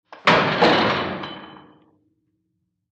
PrisonCellDoorSlam PE802003
DOORS VARIOUS PRISON DOORS: Cell door slam, medium fast.